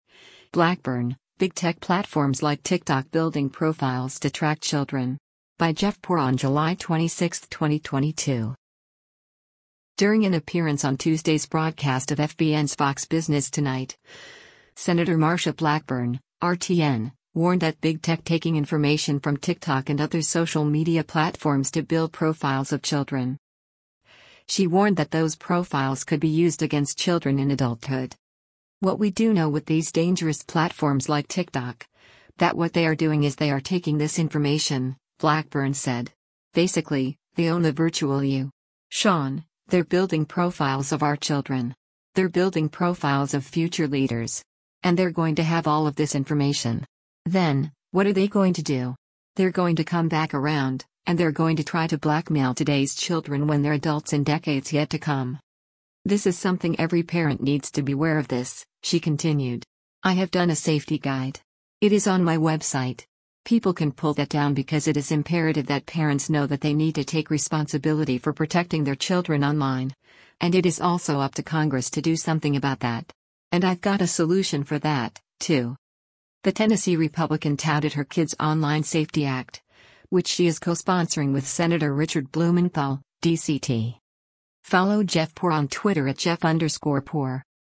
During an appearance on Tuesday’s broadcast of FBN’s “Fox Business Tonight,” Sen. Marsha Blackburn (R-TN) warned that Big Tech taking information from TikTok and other social media platforms to build profiles of children.